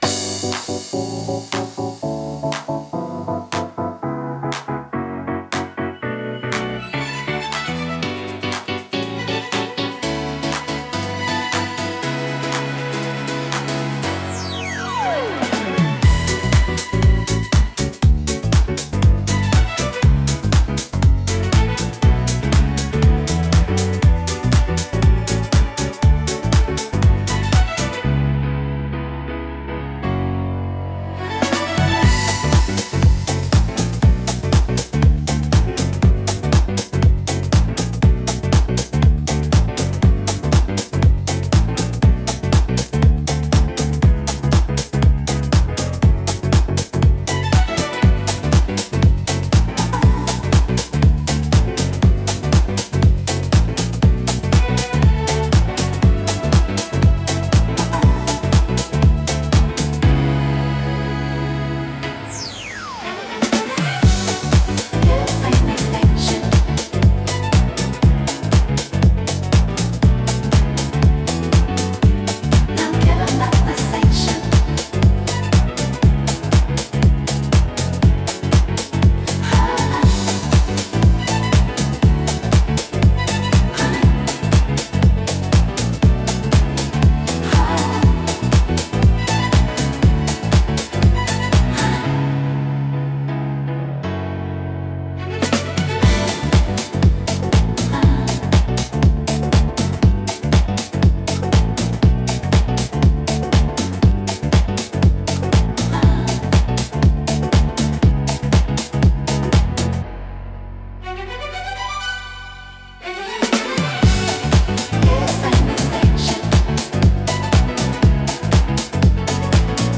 特に、ポジティブで元気な印象を与えたい時に適しています。